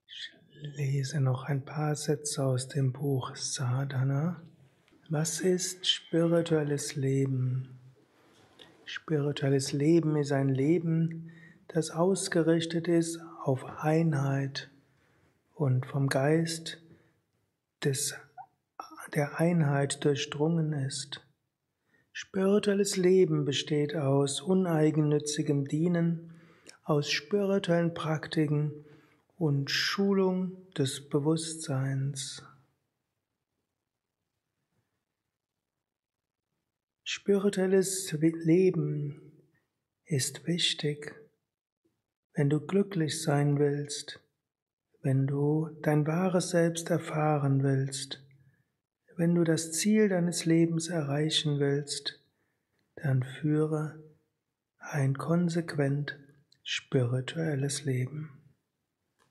Kurzvorträge
eine Aufnahme während eines Satsangs gehalten nach einer
Meditation im Yoga Vidya Ashram Bad Meinberg.